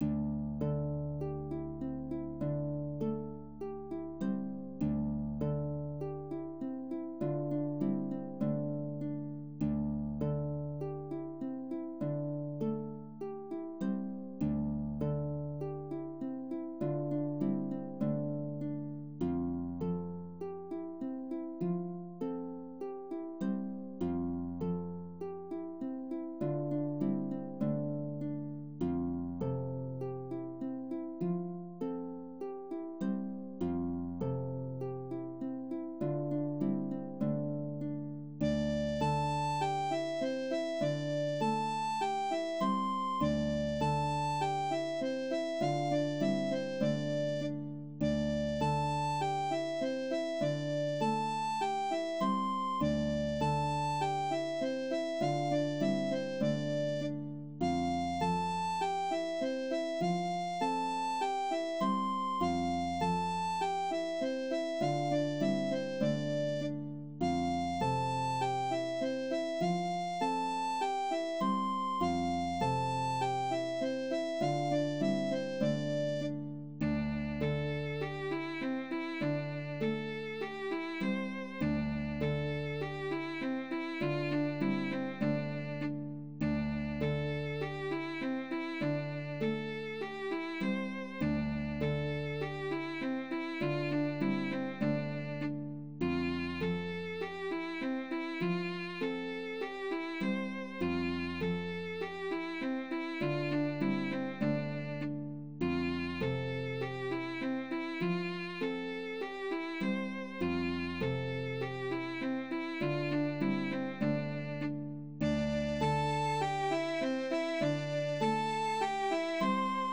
- ATELIER "ENSEMBLE DE HARPES" -
Polka_MacMahon_100.wav